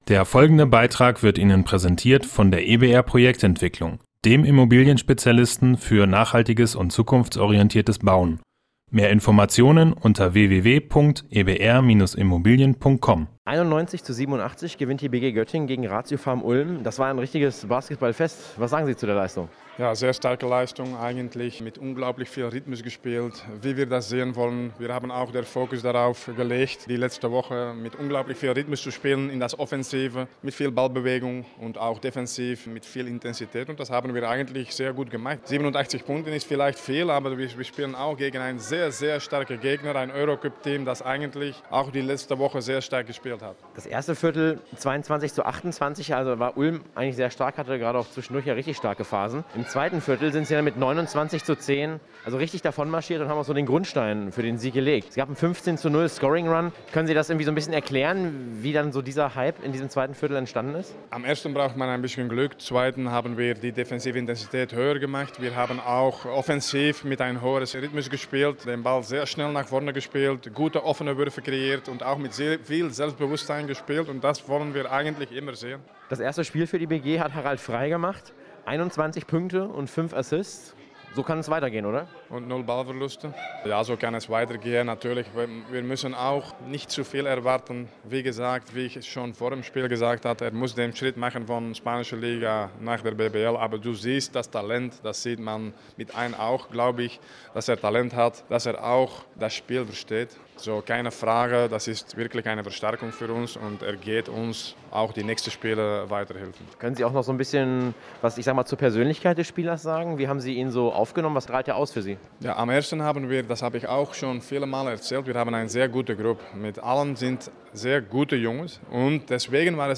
Samstagabend waren zwar nur 500 Zuschauer in der Göttinger S-Arena, als die BG Göttingen ratiopharm Ulm empfing.